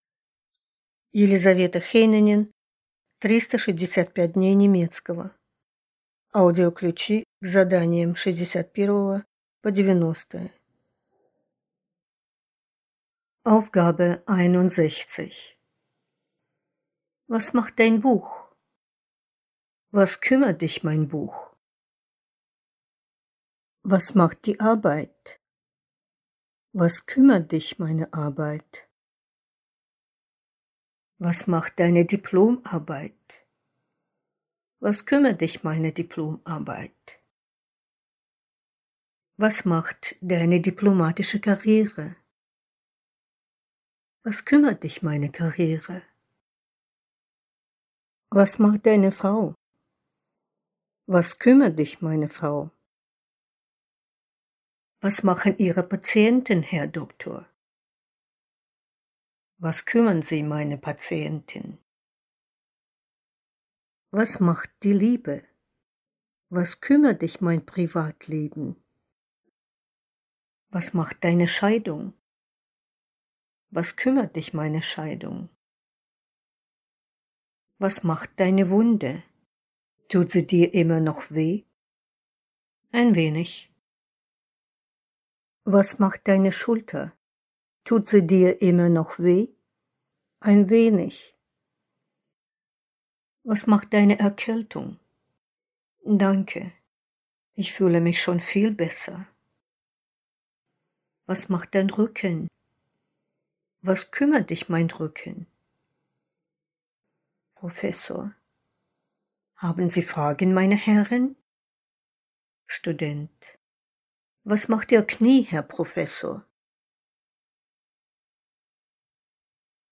Аудиокнига 365 дней немецкого. Тетрадь третья | Библиотека аудиокниг